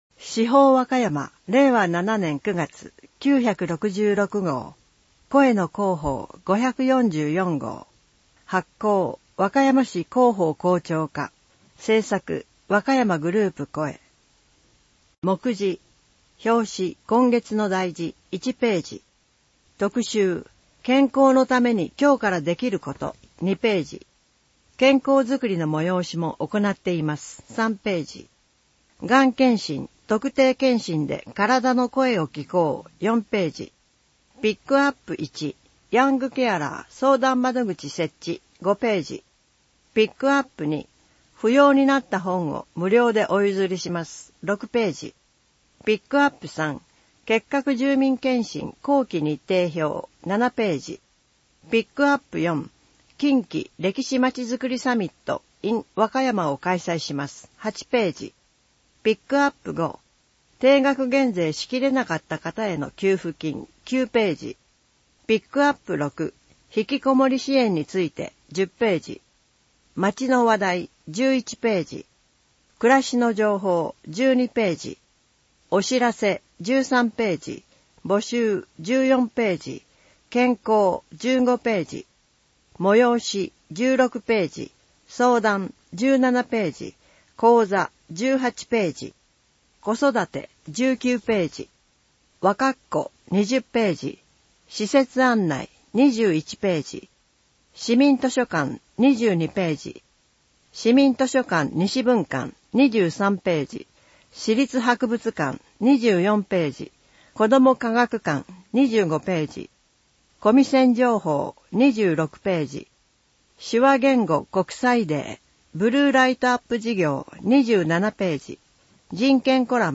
声の市報